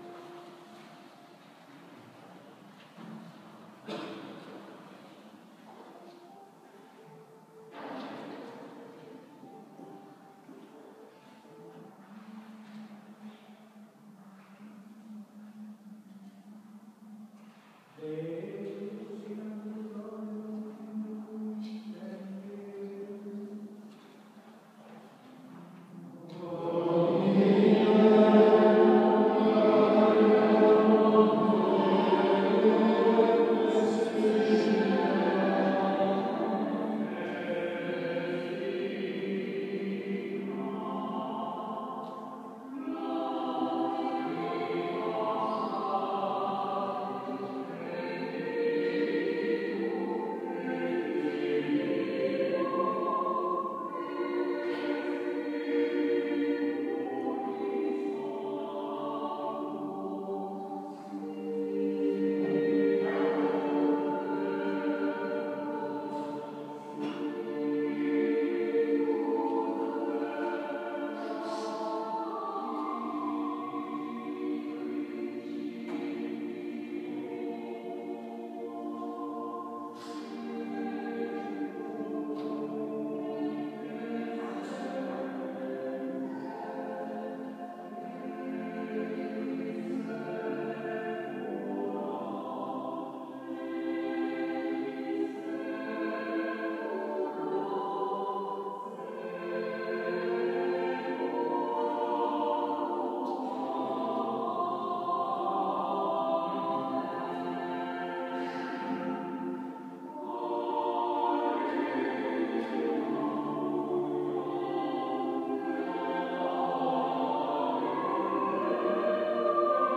Christ is King! [Vespers: AUDIO]